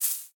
step_grass.ogg